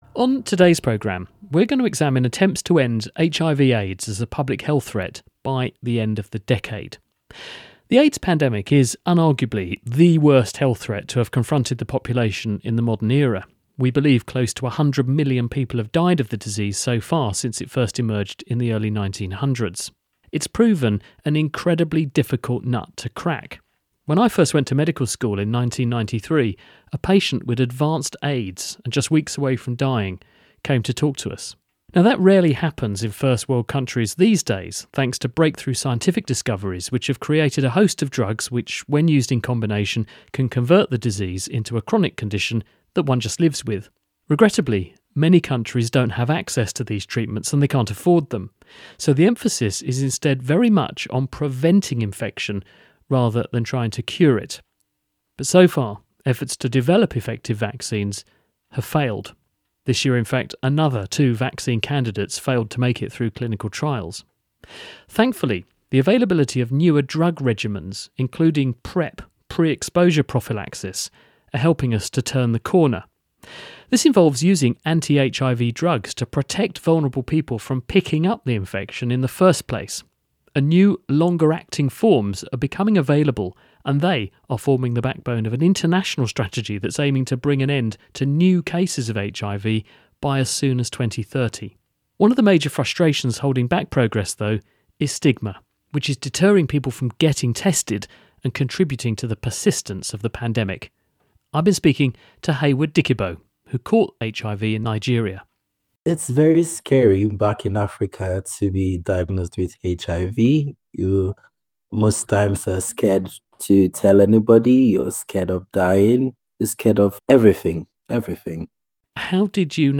Interviews with Scientists